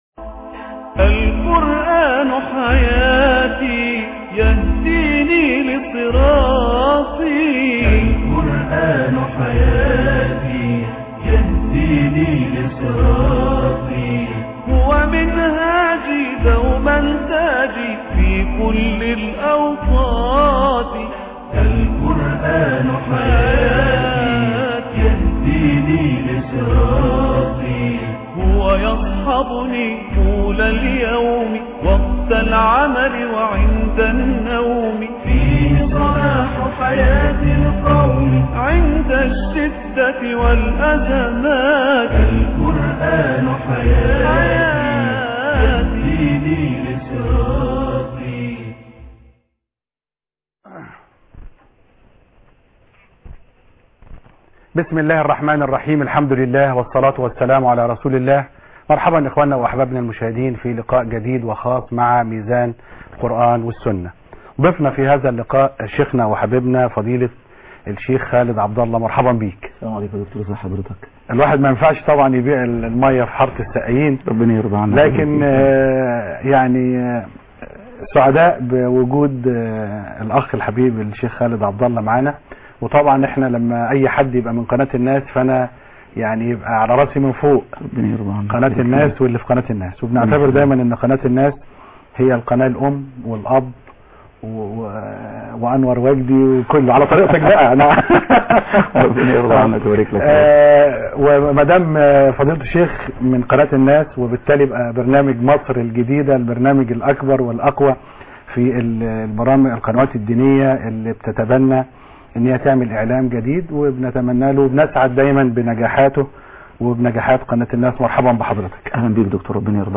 حوار جرئ